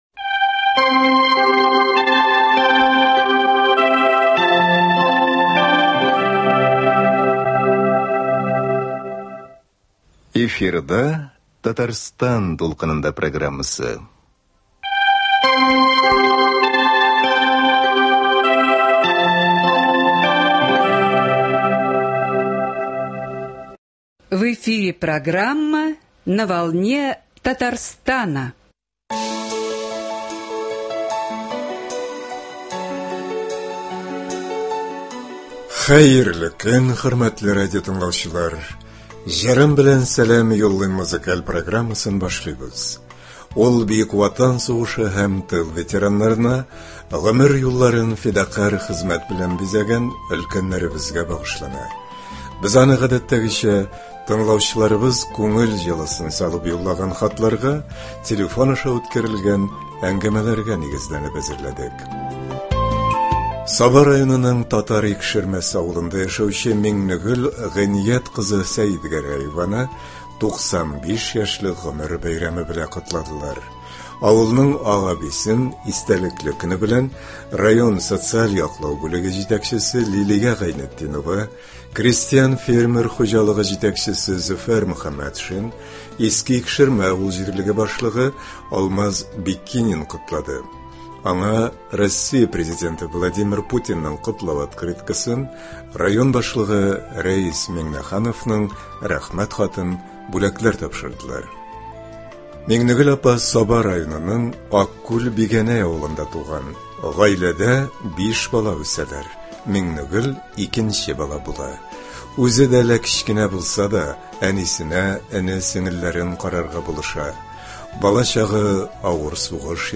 Ветераннар өчен музыкаль программа.